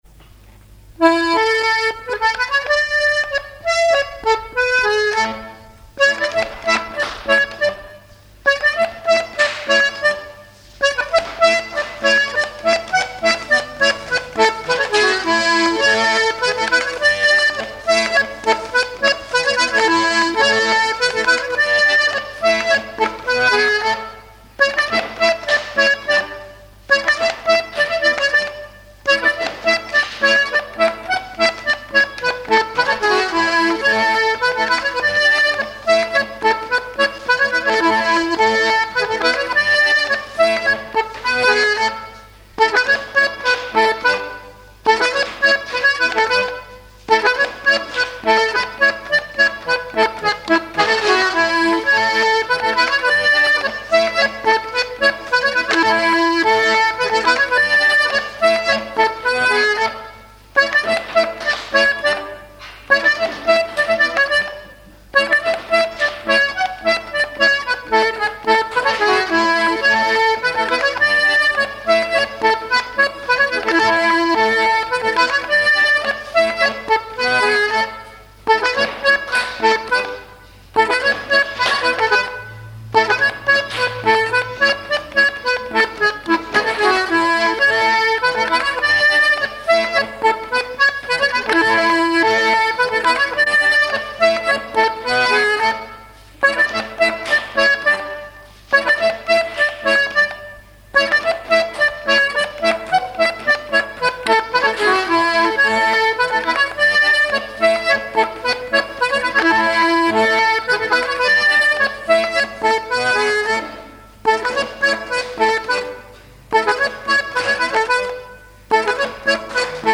danse : pas d'été
airs de danse à l'accordéon diatonique
Pièce musicale inédite